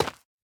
Minecraft Version Minecraft Version latest Latest Release | Latest Snapshot latest / assets / minecraft / sounds / block / dripstone / break4.ogg Compare With Compare With Latest Release | Latest Snapshot
break4.ogg